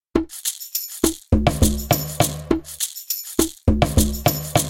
标签： 打击乐器 手鼓 器乐 摇滚
声道立体声